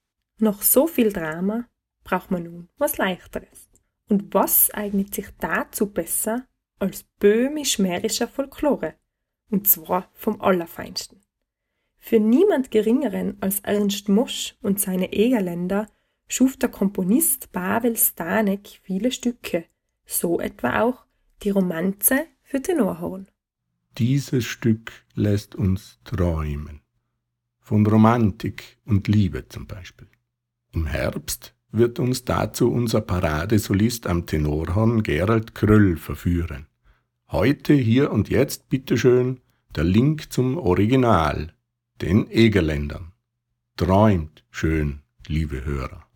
kurze Moderation